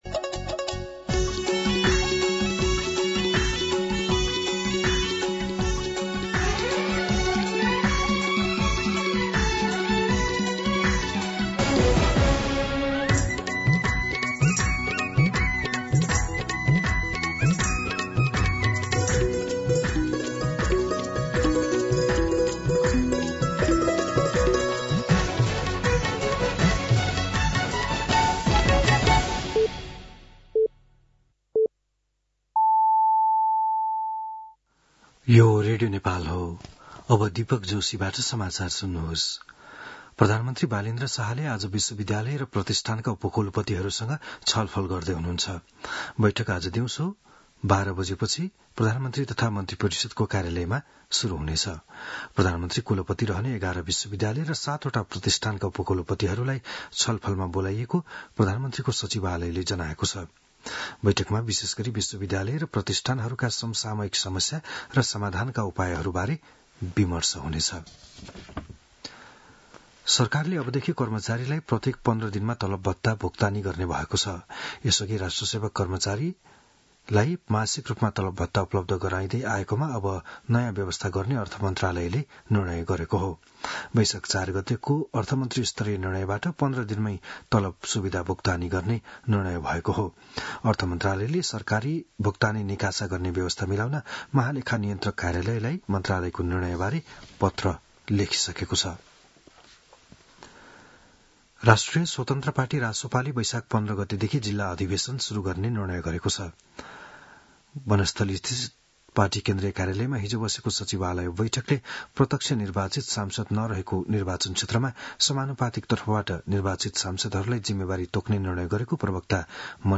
बिहान ११ बजेको नेपाली समाचार : ७ वैशाख , २०८३